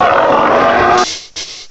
cry_not_hippowdon.aif